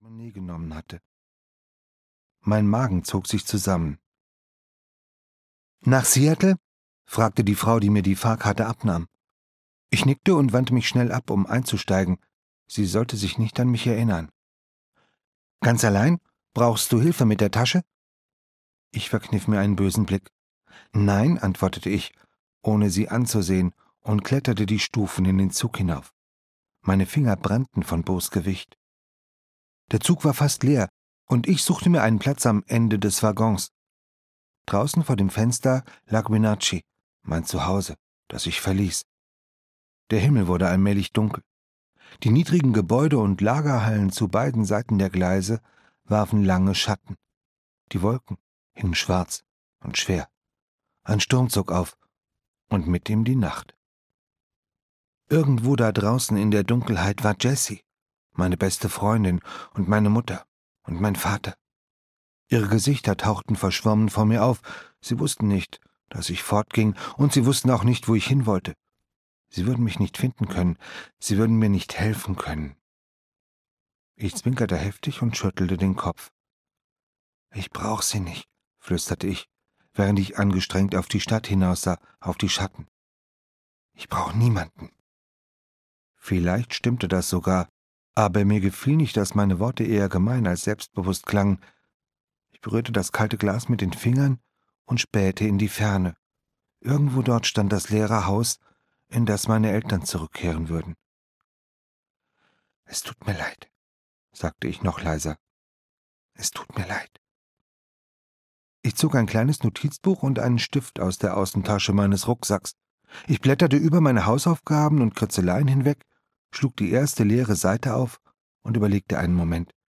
Die wirkliche Wahrheit - Dan Gemeinhart - Hörbuch